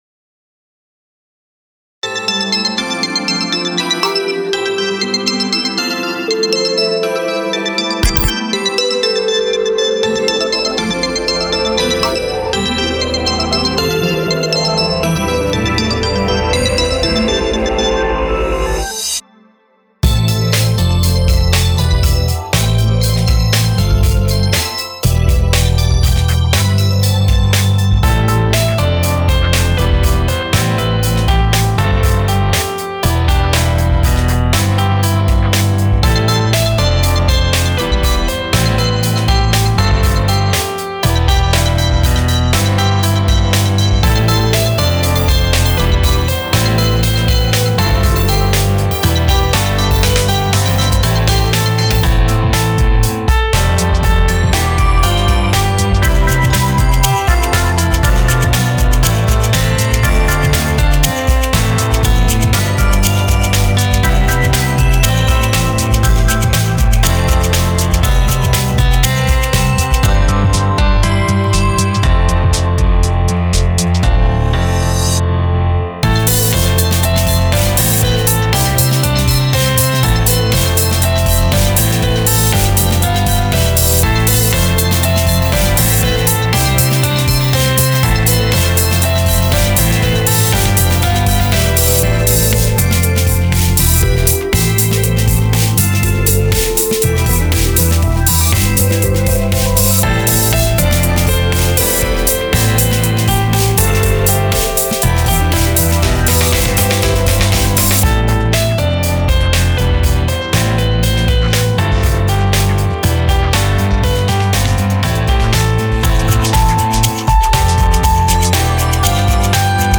ダーク目で、ソリッドな曲が続いたので次はしっとりした曲か、カラフルな曲を作ろうと思います。